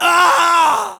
scream.m4a